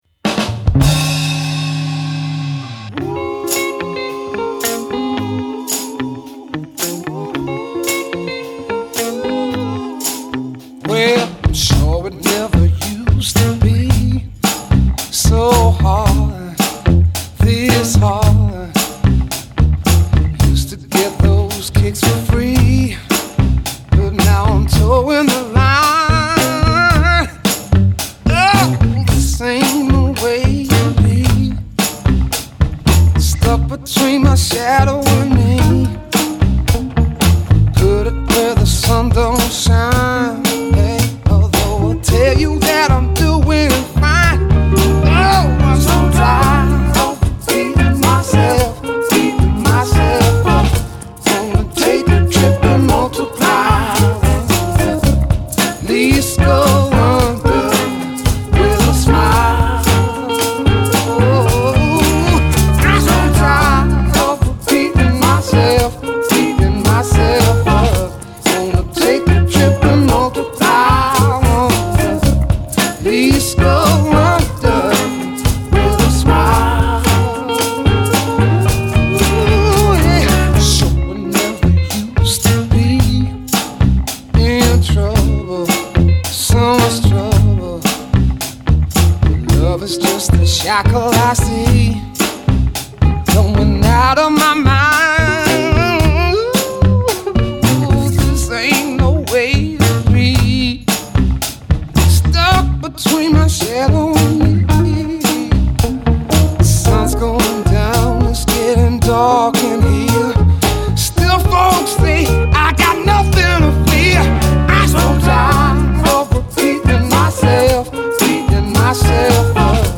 is still a benchmark for modern Soul.